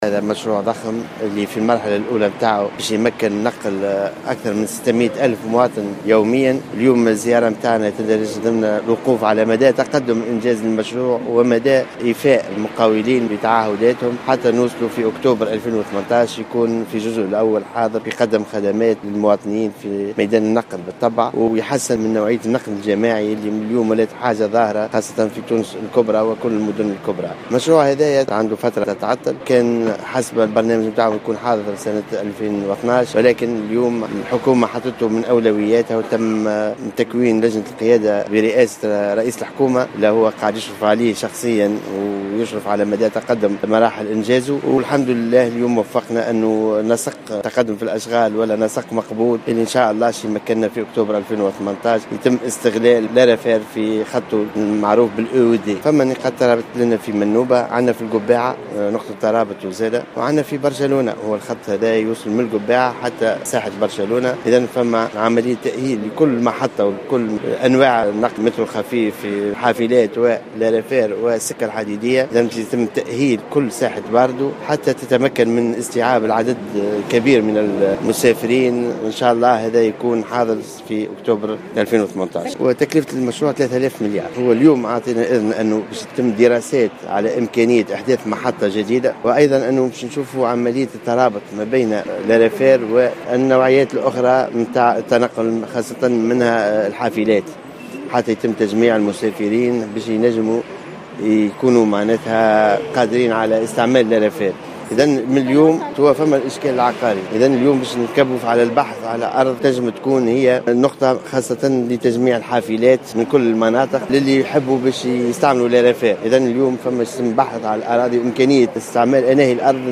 أعلن وزير النقل أنيس غديرة في تصريح للجوهرة أف أم خلال زيارته اليوم الجمعة 8 أفريل 2016 إلى ولاية منوبة أن القسط الأول من مشروع النقل الحديدي السريع بمنوبة سيكون جاهزا للاستعمال في 2018.